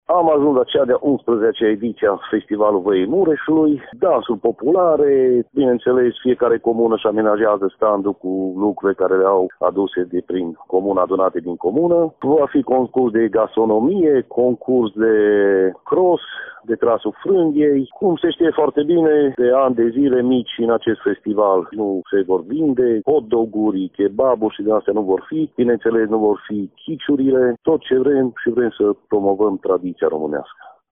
Directorul festivalului, primarul comunei Răstoliţa, Marius Lircă.